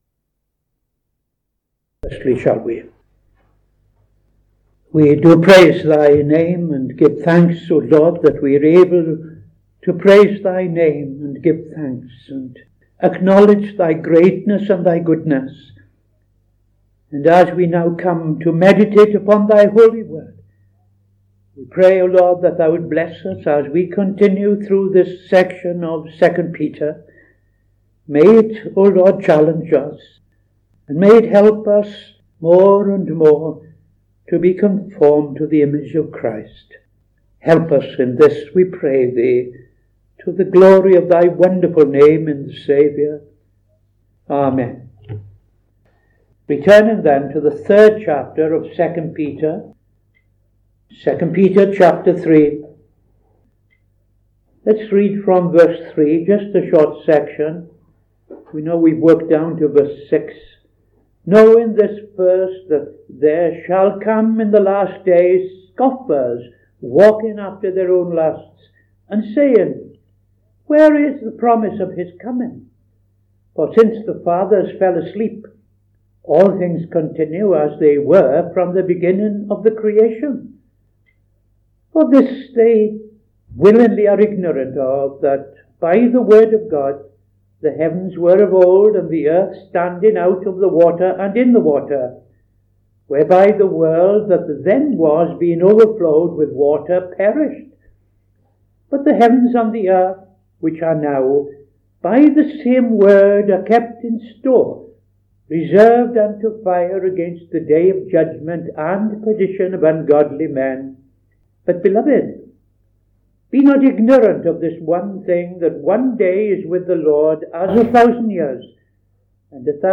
Opening Prayer and Reading II Peter 3:3-9